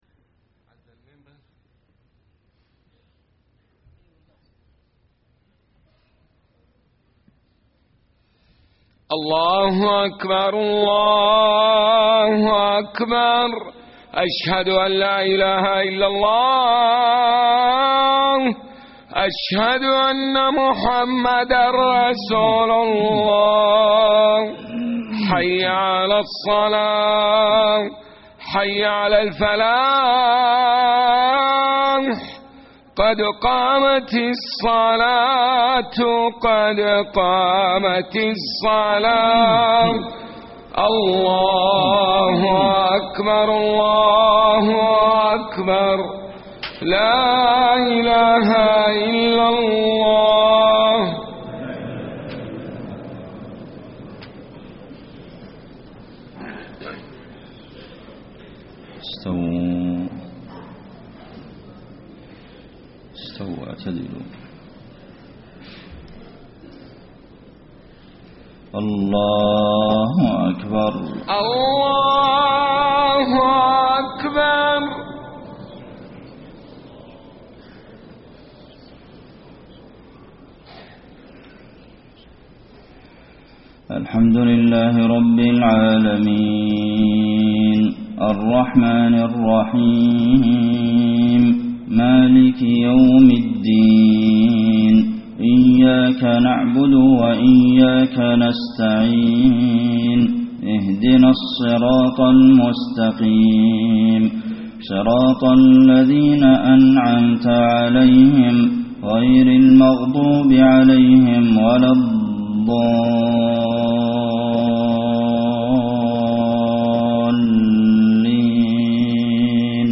صلاة الفجر 6-8-1434 من سورة نوح > 1434 🕌 > الفروض - تلاوات الحرمين